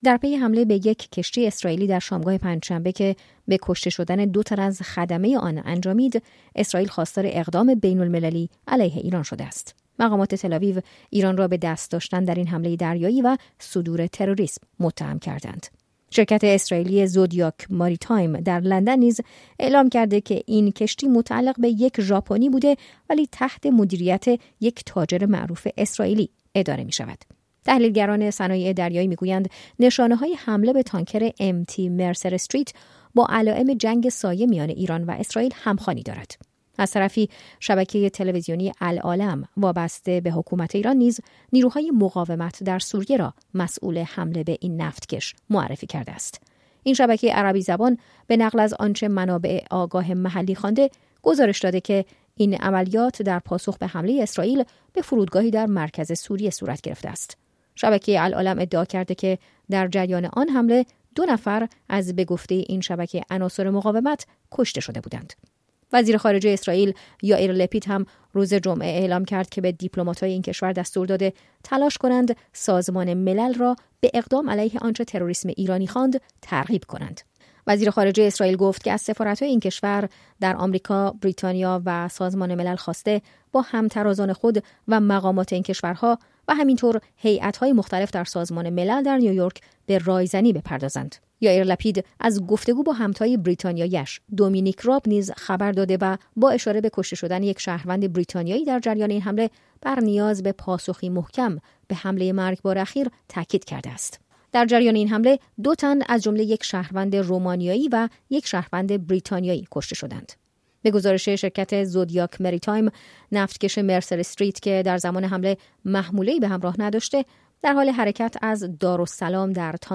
جزئیات بیشتر در گزارشی